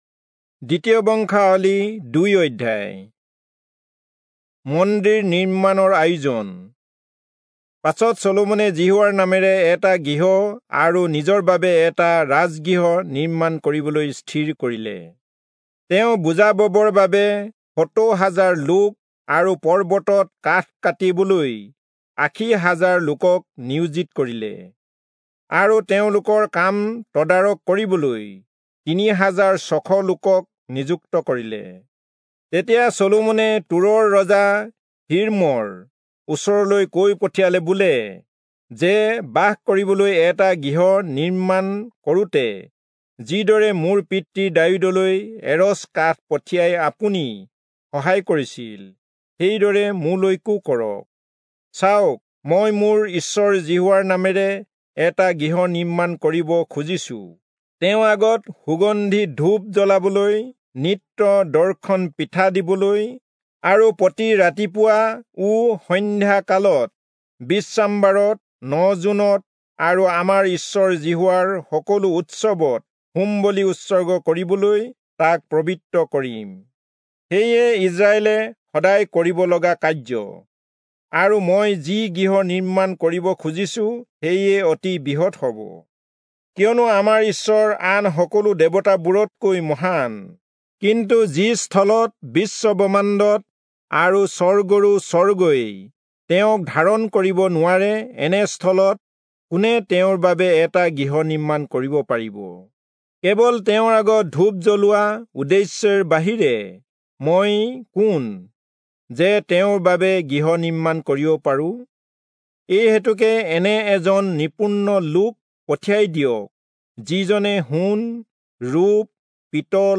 Assamese Audio Bible - 2-Chronicles 32 in Mrv bible version